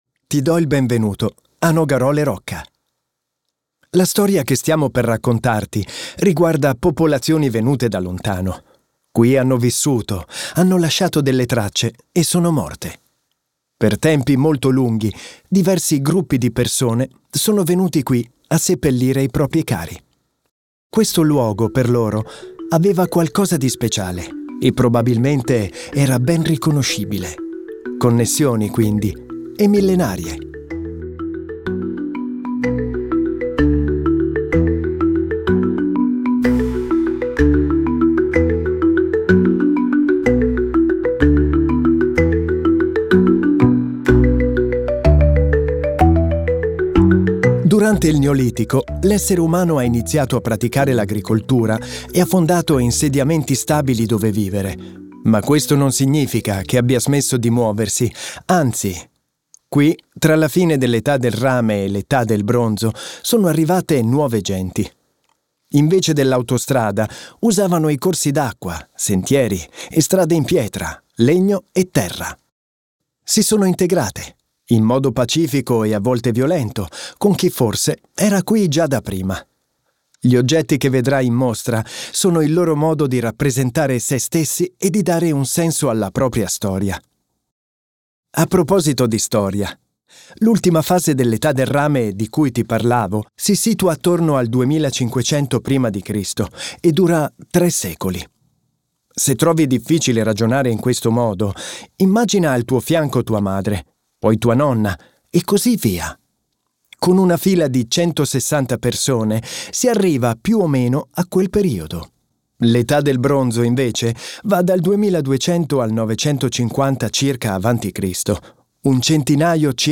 Un approfondimento sonoro
nogarole_rocca_audioguida_ita_01